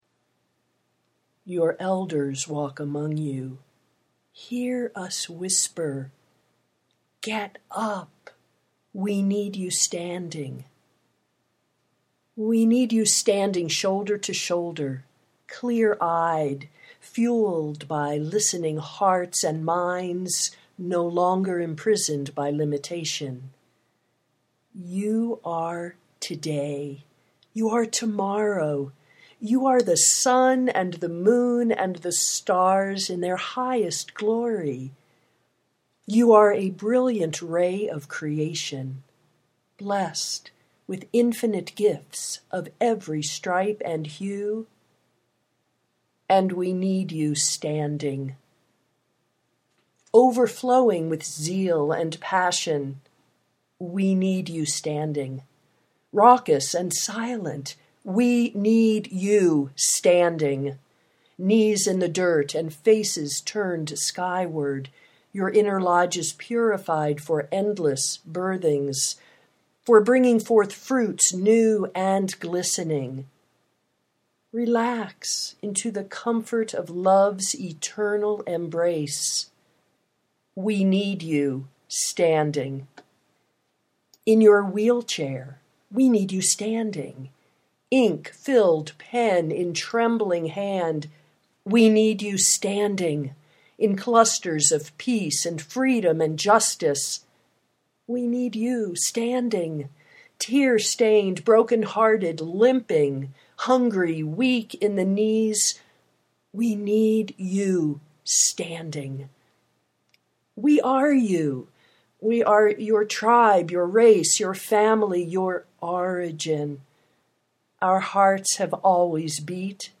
we need you standing (audio poetry 3:39)